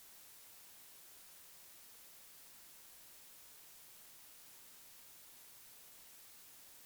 During recording, there is a probability of sustained noise of around -45db, which can be restored after resetting the IC.
They conducted the following investigation (the problem is that white noise of -45dB is added to each channel, as shown in the recording file below, which appears probabilistically)
Noise recording file